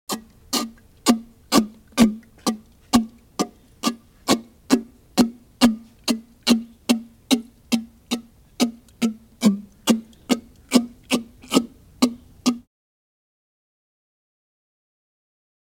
جلوه های صوتی
دانلود صدای فیل 2 از ساعد نیوز با لینک مستقیم و کیفیت بالا